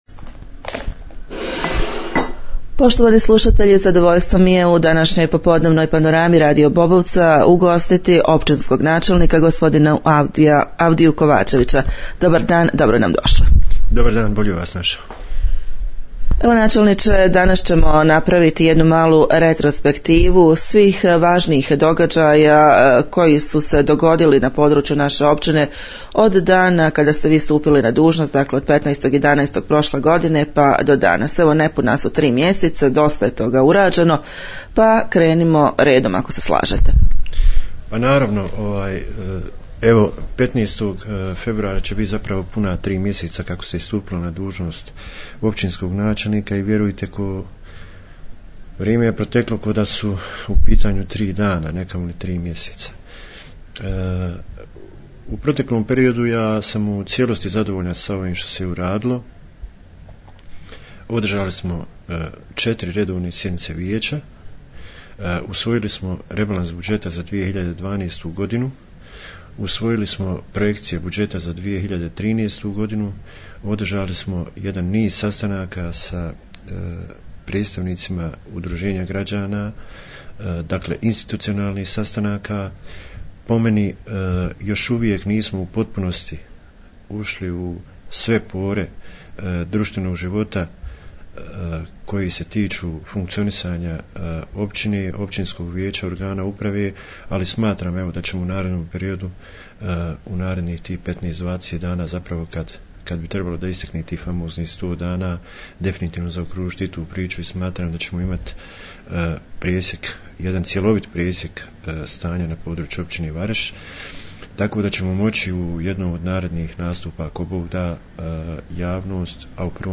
O započetim projektima govori načelnik Općine
Zbog velikih obveza načelnika, razgovor smo uspjeli realizirati tek danas 04.02.2013. Poslušajte što je do sada urađeno i što se sve planira u budućnosti...